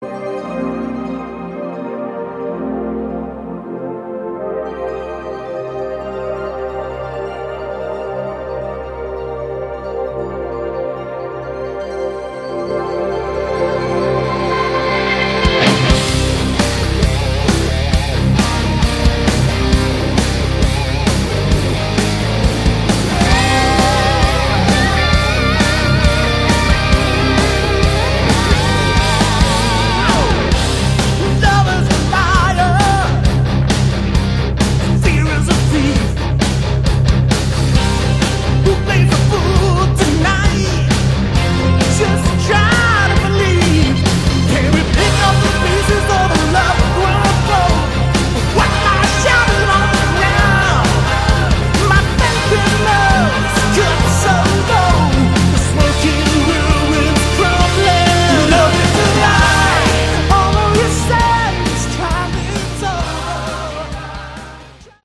Category: AOR/ Melodic Rock